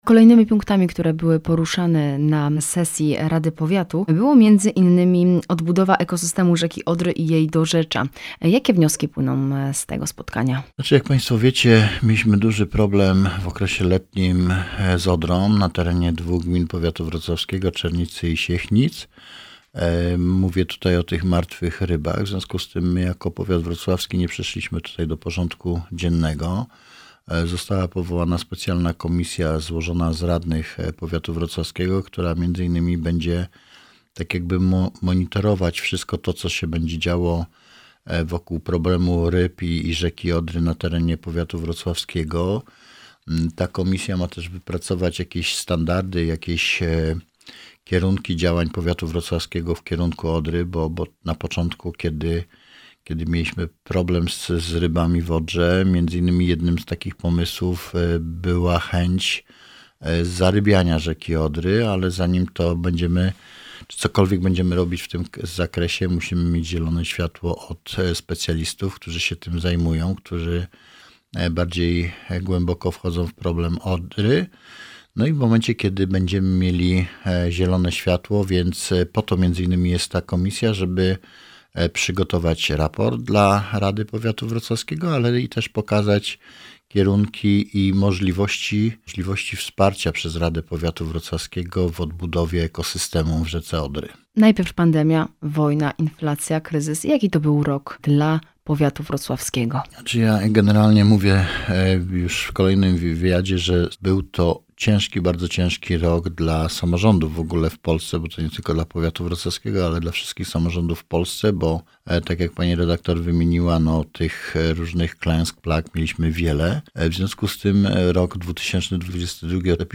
Sesja rady Powiatu Wrocławskiego, uchwalenie budżetu na rok 2023, utworzenie Młodzieżowej Rady Powiatu Wrocławskiego, a także podsumowanie inwestycji rozmawiamy z Romanem Potockim – Starostą Powiatu Wrocławskiego.